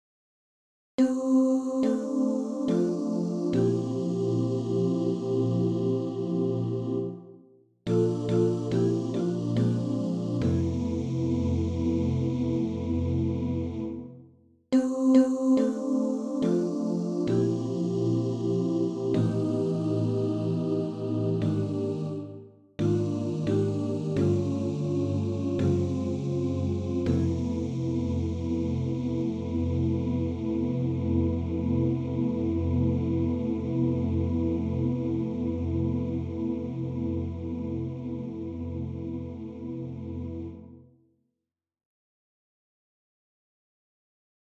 Key written in: F Major
How many parts: 5
Type: Barbershop
All Parts mix: